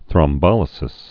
(thrŏm-bŏlĭ-sĭs)